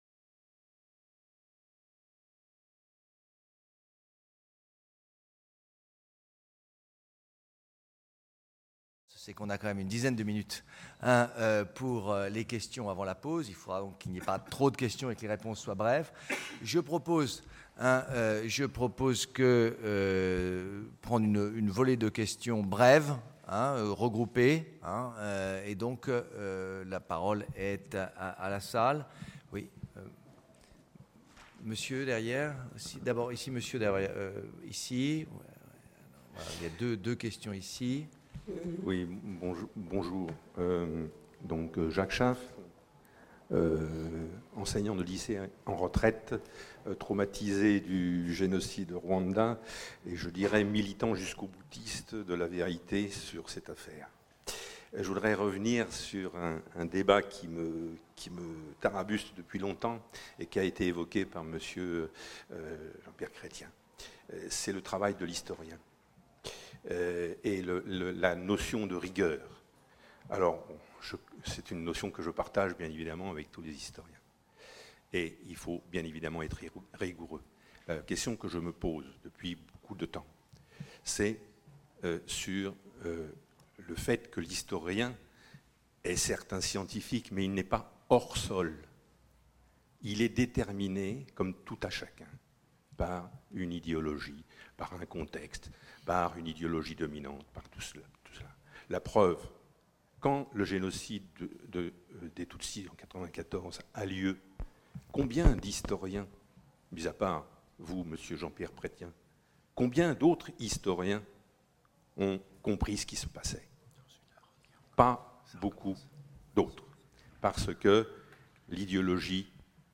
Questions suite aux interventions